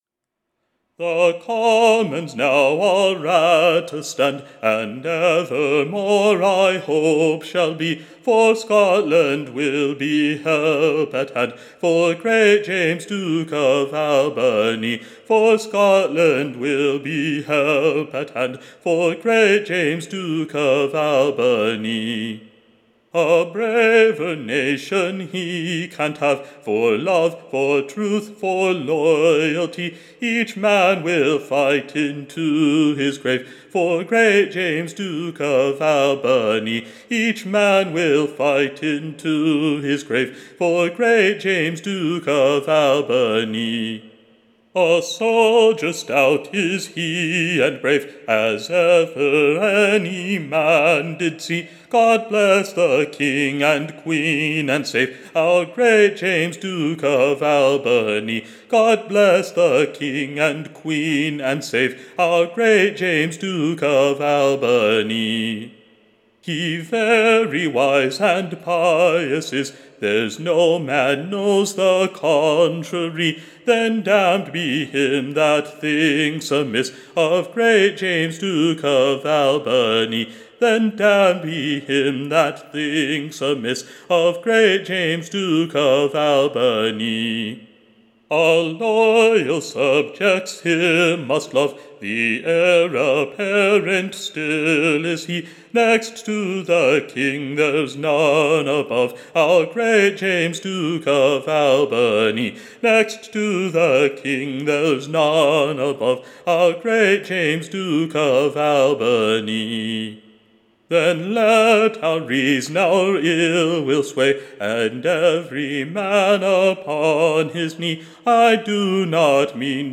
Recording Information Ballad Title The HONOUR of Great / YORK and ALBANY, / A Loyal Song.